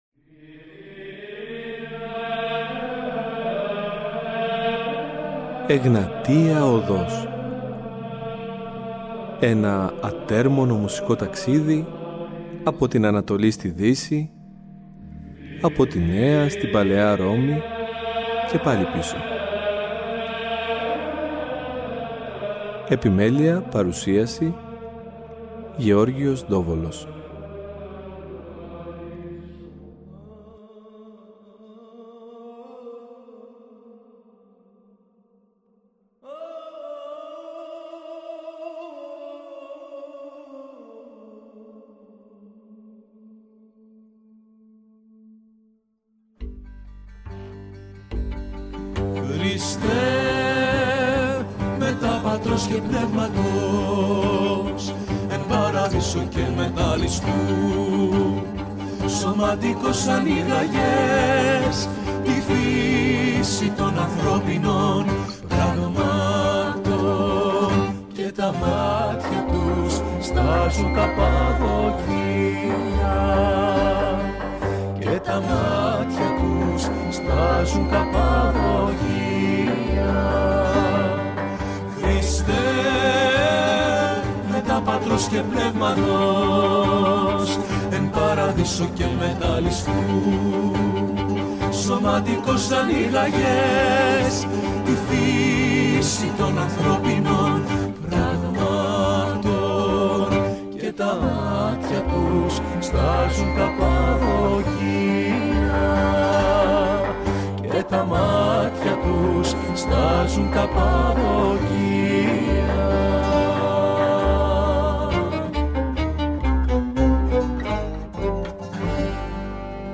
Συζητώντας